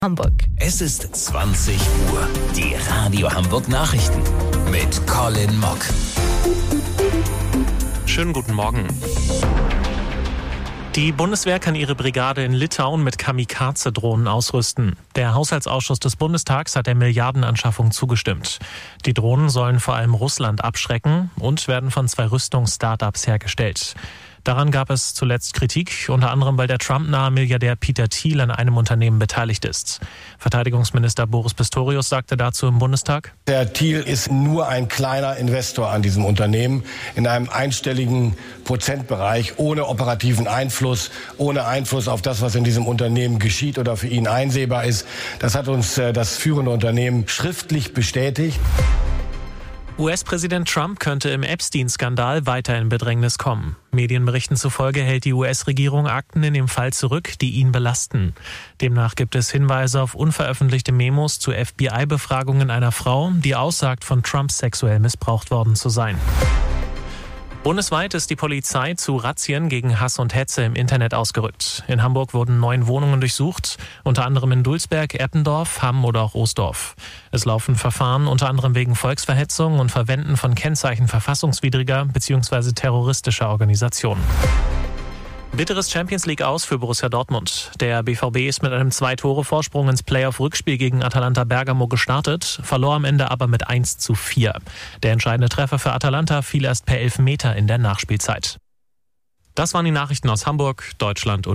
Radio Hamburg Nachrichten vom 26.02.2026 um 20 Uhr